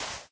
sand2.ogg